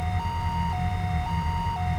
「Future Police from Future」のようなプリセットではシンセセクションの音でパトカーのサイレンのような音を表現しています。